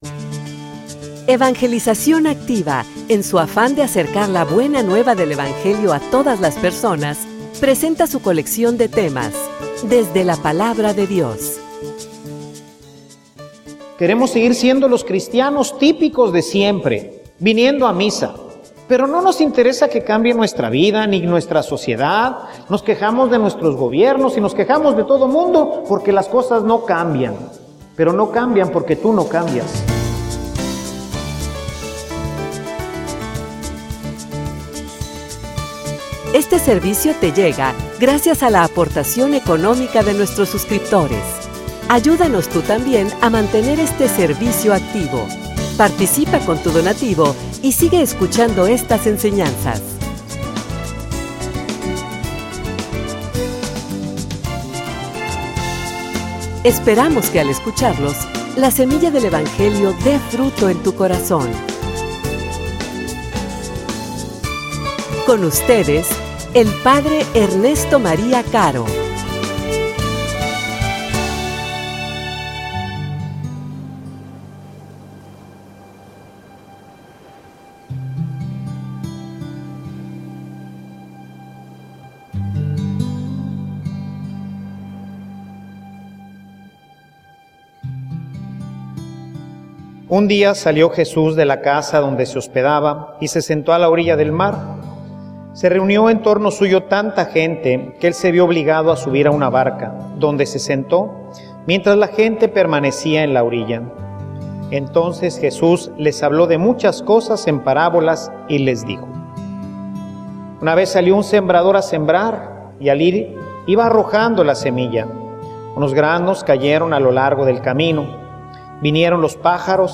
homilia_Donde_esta_tu_Biblia.mp3